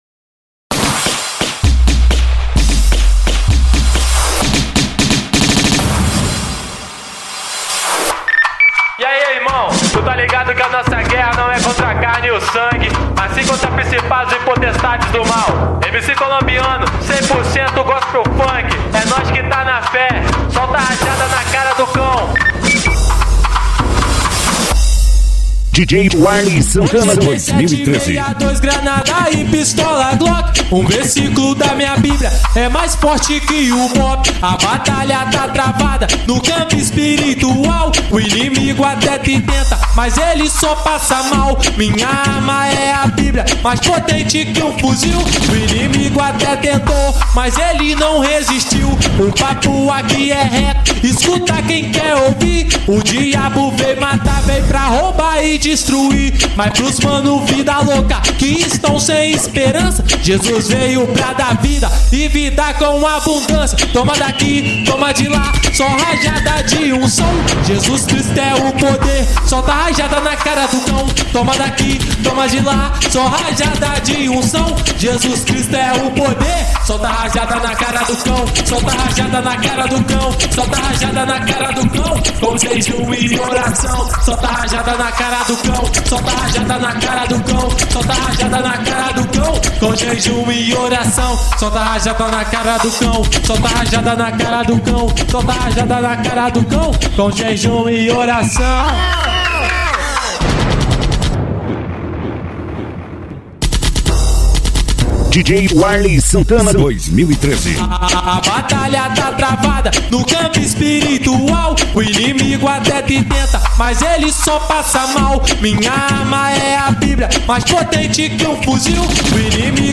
gospel.